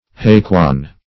Search Result for " haikwan" : The Collaborative International Dictionary of English v.0.48: Haikwan \Hai"kwan"\ (h[imac]"kw[aum]n"), n. [Chin.